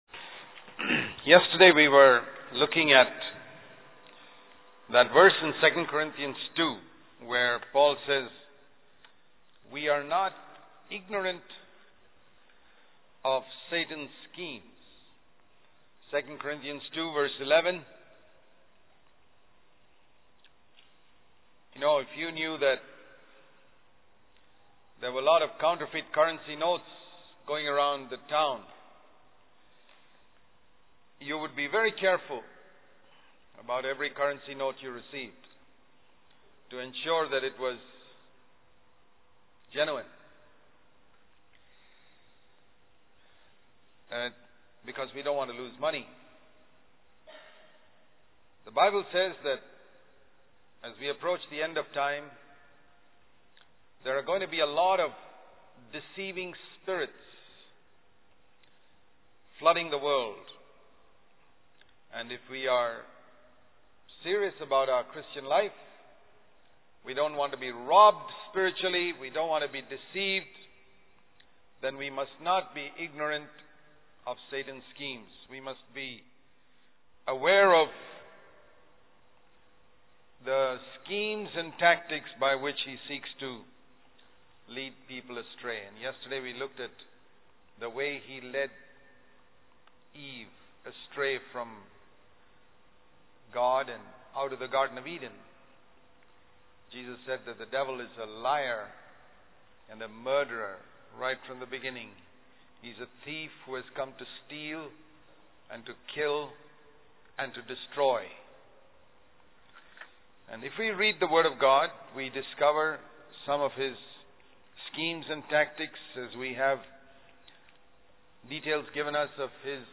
In this sermon, the preacher focuses on the book of Job and highlights the godly character of Job, who was also a wealthy man and a father of ten children.